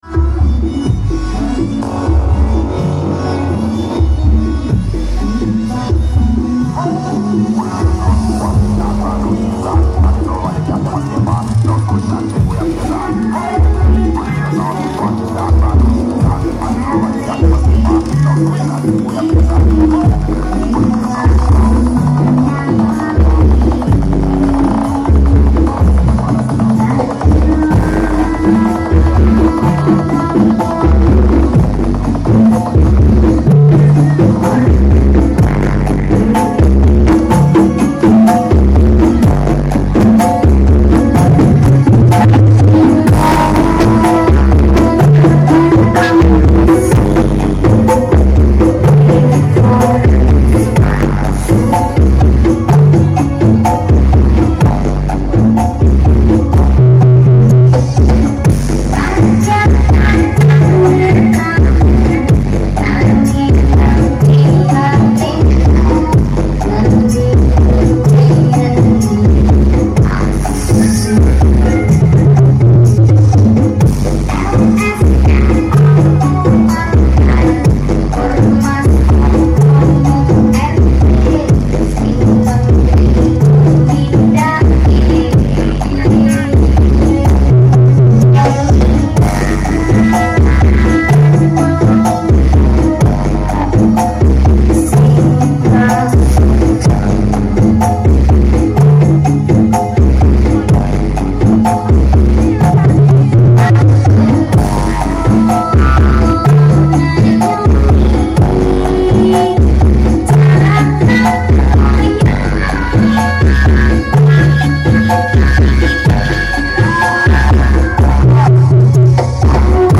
MINIONS AUDIO KARNAVAL SUKOPURO JABUNG